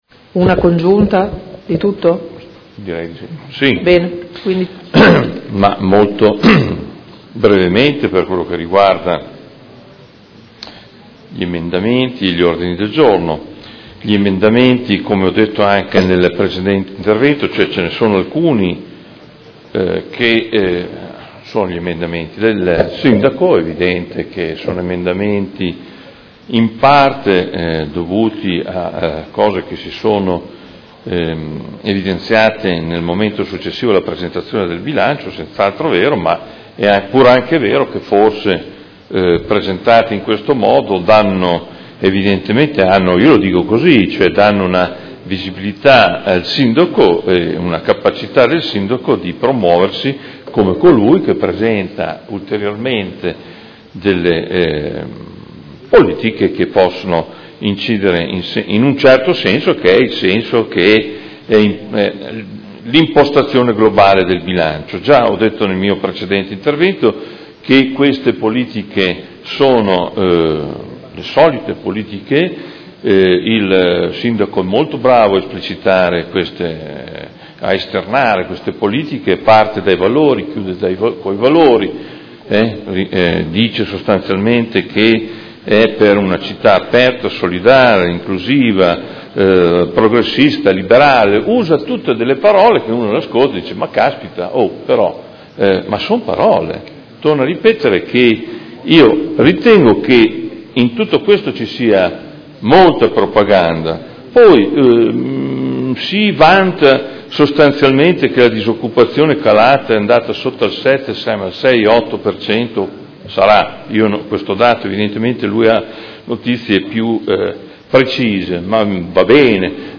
Seduta del 26 gennaio. Bilancio preventivo: Dichiarazioni di voto